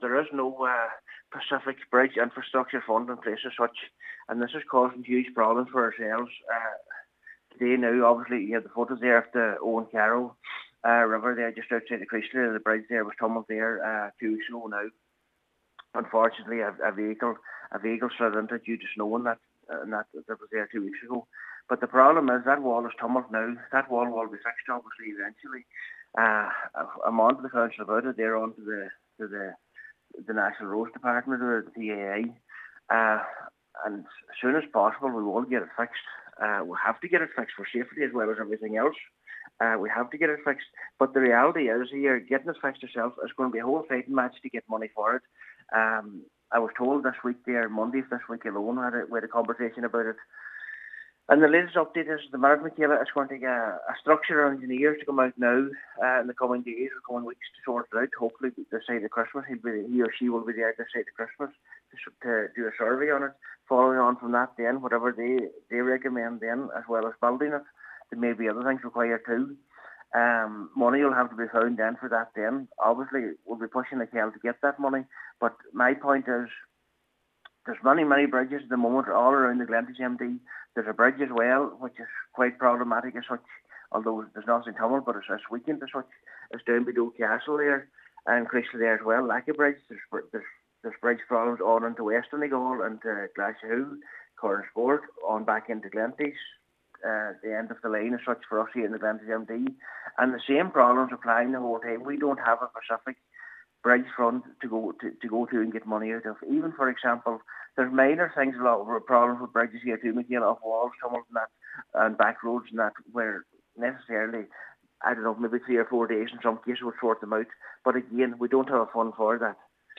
Councillor Michael McClafferty is calling for a review of how bridge repairs are funded: